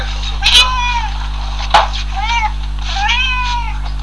よく鳴く。キャットフードがほしいときなどはしつこいくらいに鳴く。
鳴き声